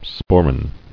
[spor·ran]